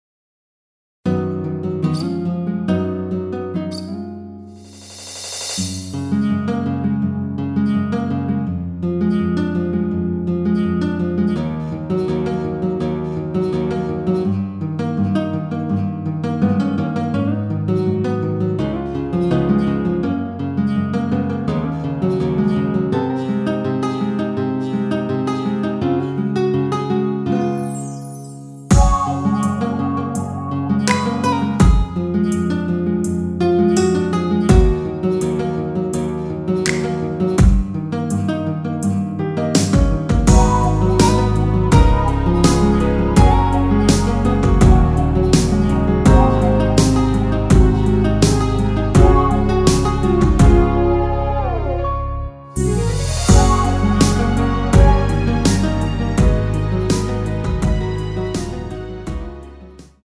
Gb
◈ 곡명 옆 (-1)은 반음 내림, (+1)은 반음 올림 입니다.
앞부분30초, 뒷부분30초씩 편집해서 올려 드리고 있습니다.